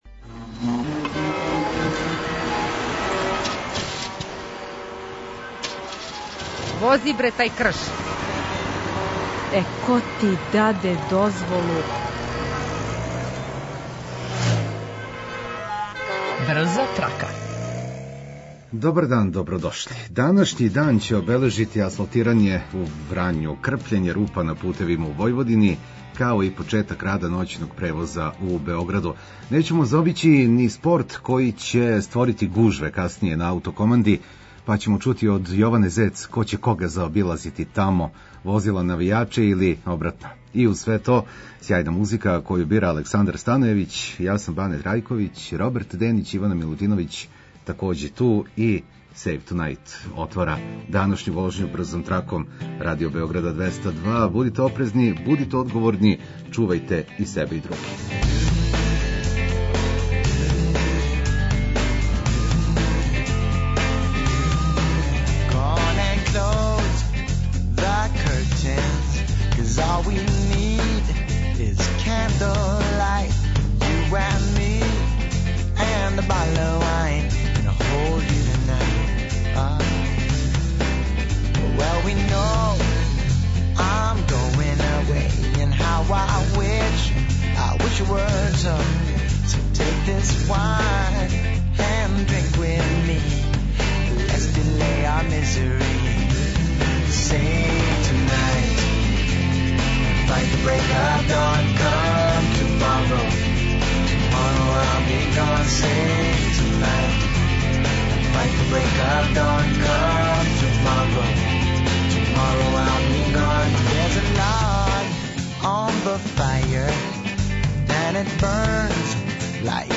Обавештавамо возаче о стању на путевима и помажемо у проналажењу алтернативних праваца. Причамо о актуелним културним дешавањима. Поправљамо расположење уз одличну музику